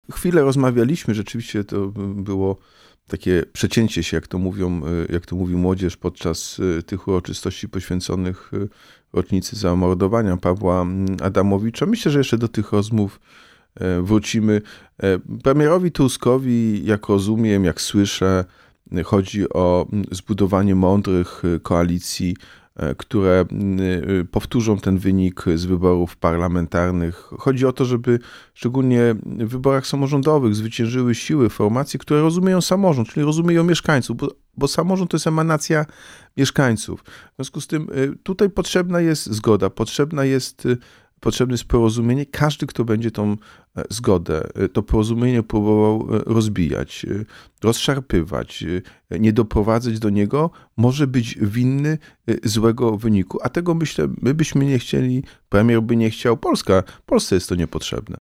Każdy, kto będzie chciał to porozumienie rozbijać, rozszarpywać, może być winny złego wyniku w wyborach. – mówił w „Porannym Gościu” Jacek Sutryk – prezydent Wrocławia.